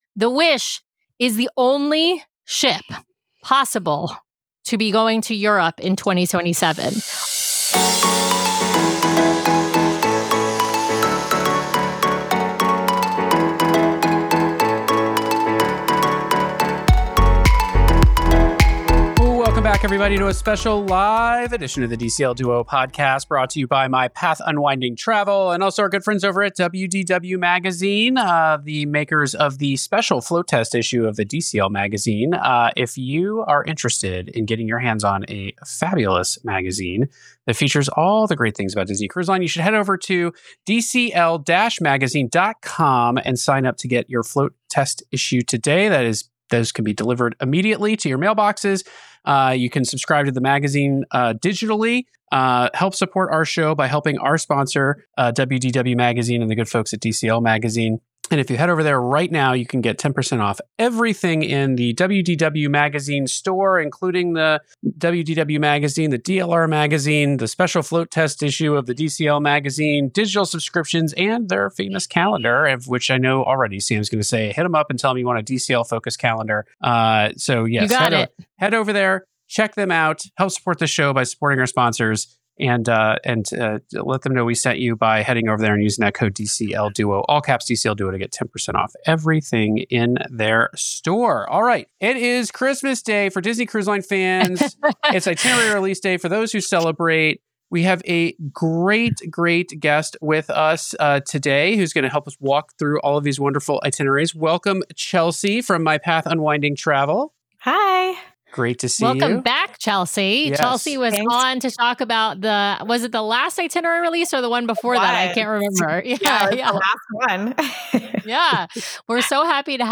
Ep. 567 - Live Bonus Show - Breaking Down Disney’s Fall 2026–Spring 2027 Cruise Itineraries